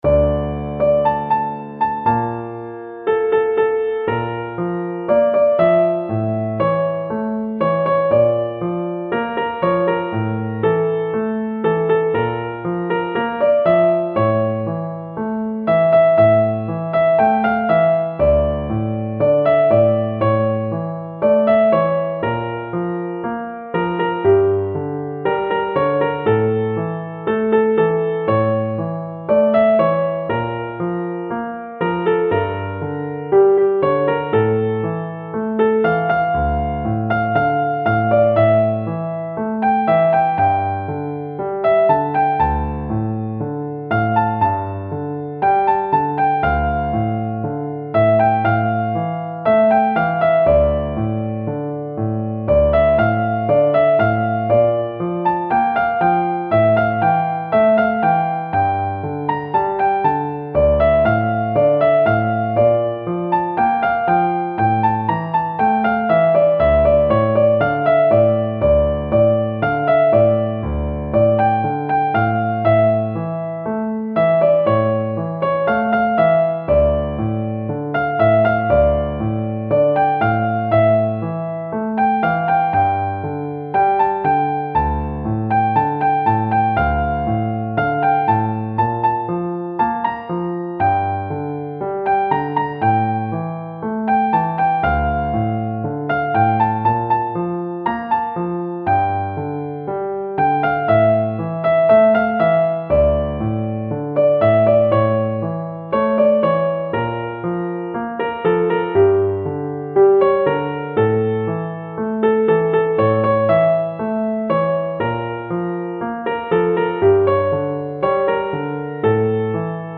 ساز : پیانو